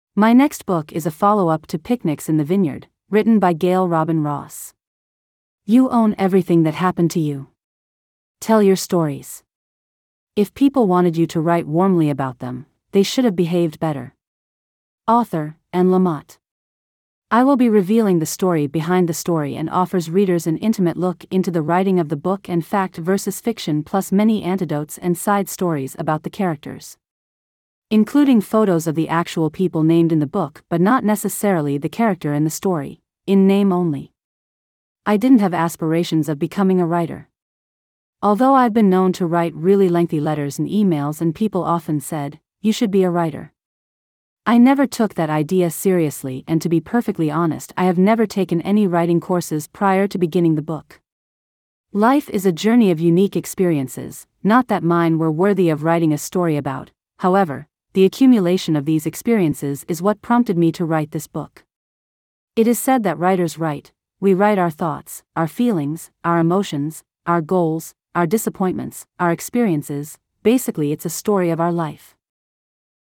Uncorked: Audio Book Introduction